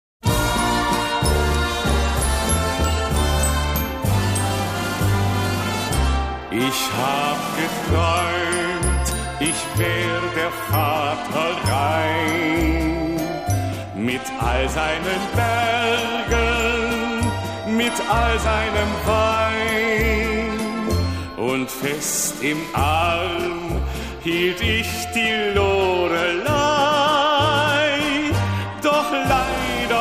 Genre: Around The World - Germany
Music from the Rhine River.